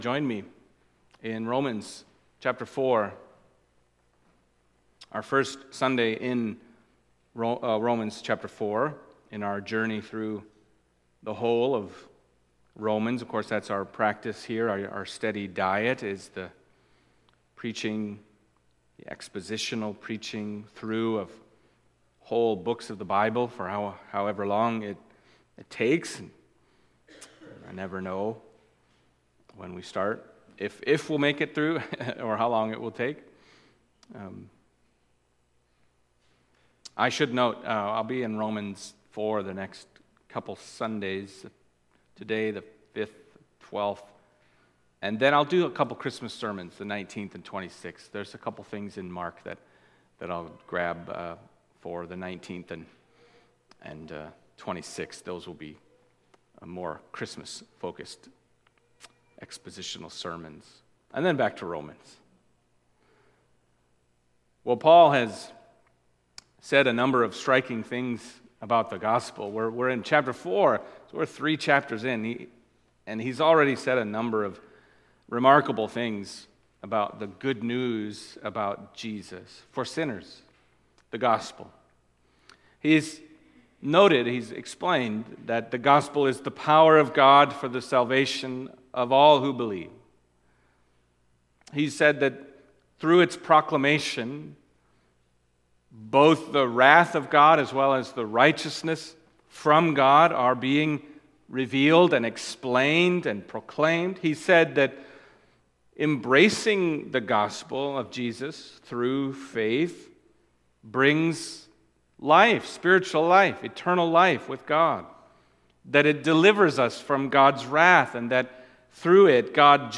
Romans 4:1-8 Service Type: Sunday Morning Romans 4:1-8 « Faith Alone Apart From Works Abraham